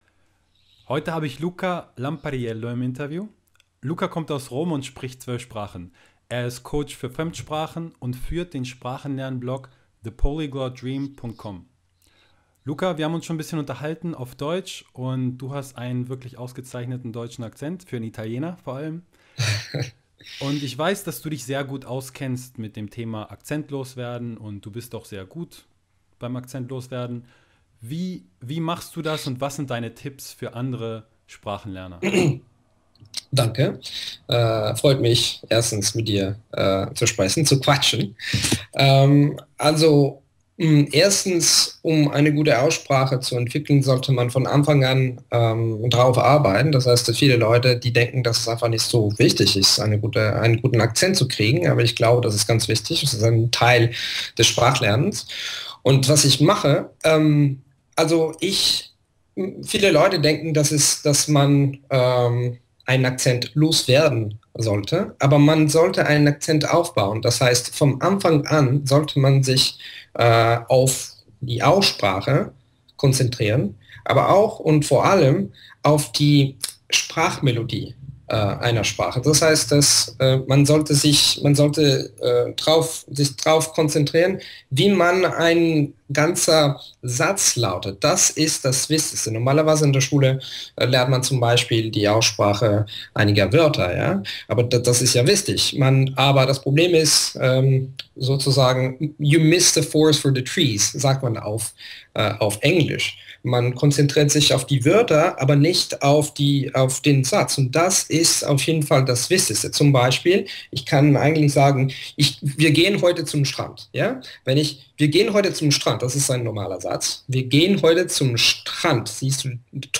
Heute habe ich einen weiteren bekannten Sprachblogger im Interview.